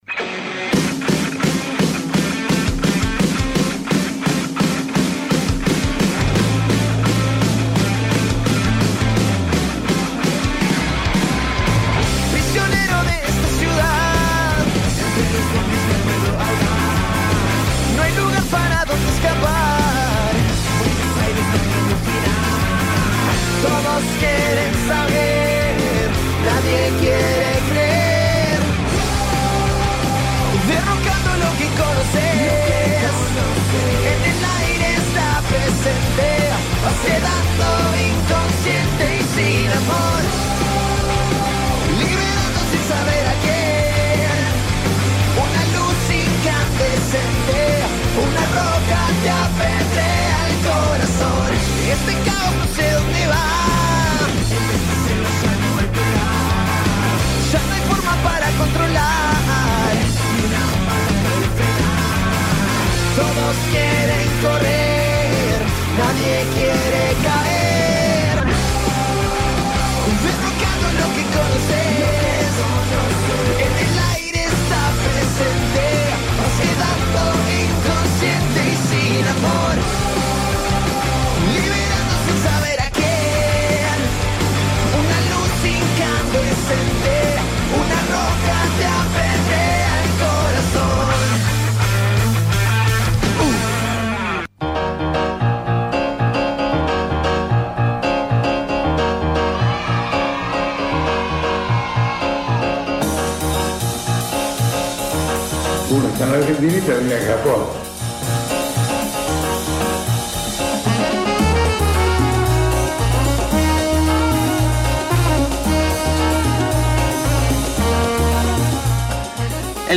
En la intro la parte vegetariana de Derrocando se sintió muy indignada frente a las fotos de Vannucci y Garfunkel cazando animales y no pudo contener su rabia al aire. Nos cuentan además como es su dieta semanal.